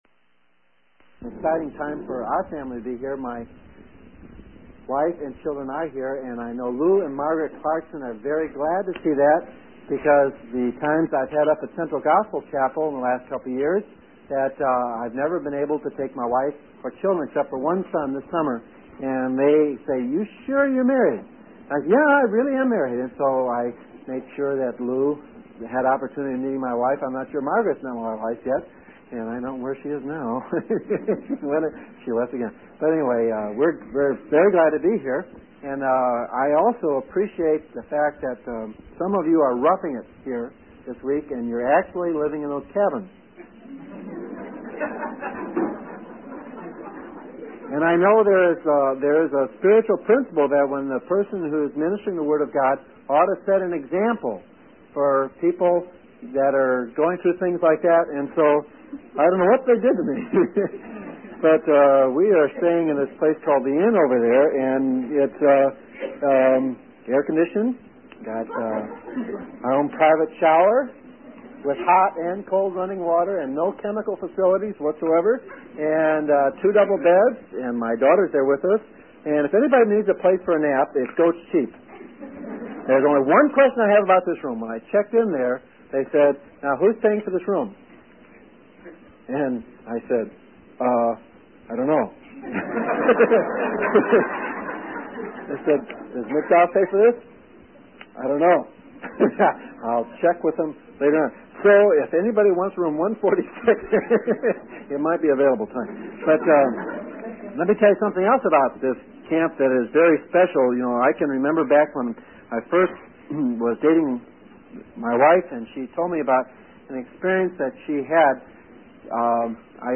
In this sermon, the speaker breaks down the passage into three parts. He begins by discussing the growth stages of children and relates it to the spiritual growth of believers.